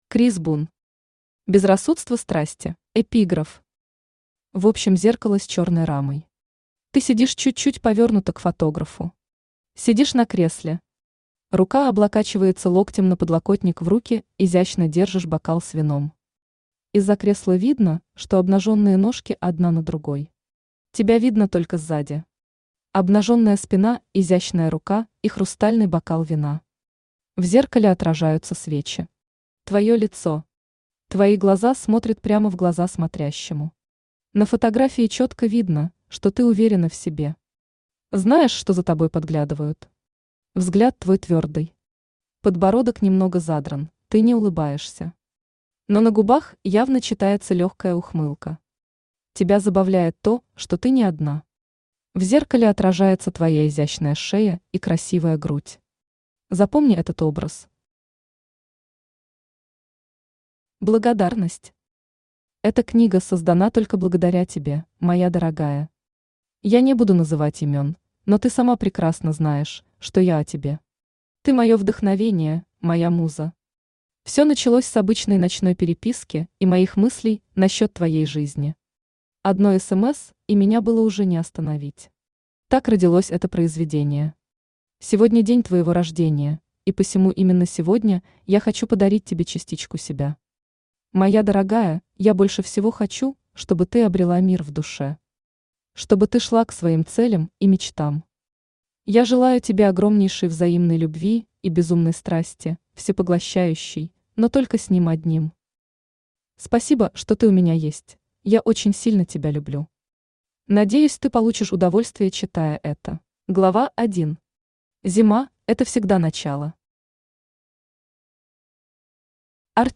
Аудиокнига Безрассудство страсти | Библиотека аудиокниг
Aудиокнига Безрассудство страсти Автор Крис Бун Читает аудиокнигу Авточтец ЛитРес.